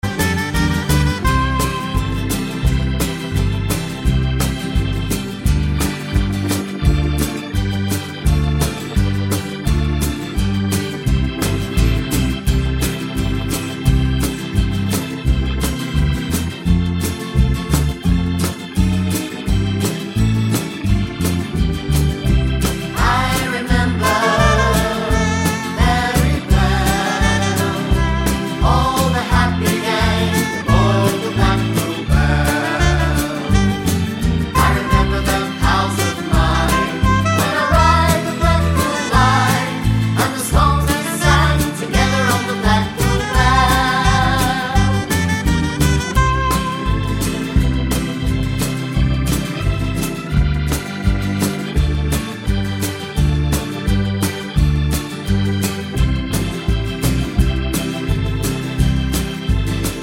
no Backing Vocals Comedy/Novelty 3:59 Buy £1.50